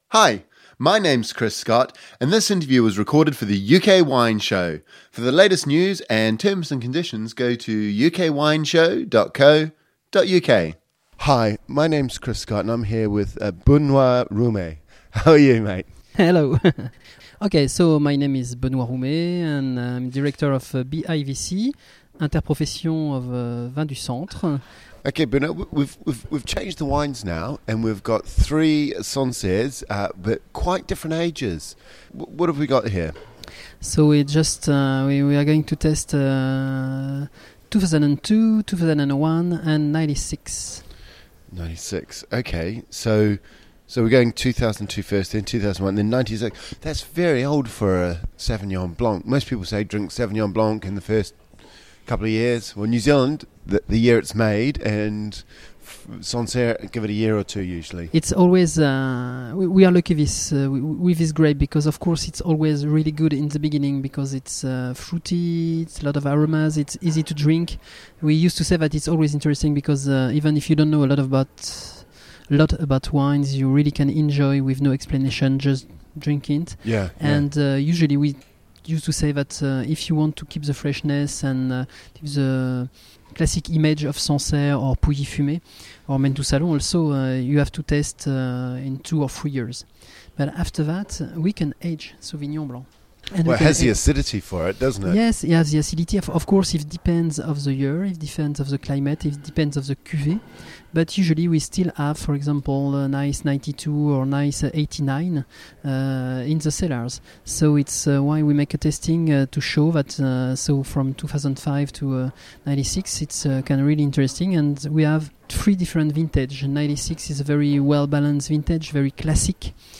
Overview In this final interview we taste 2002, 2001 and 1996 vintages of Sauvignon Blanc, a grape variety that is usually at its best when drunk young.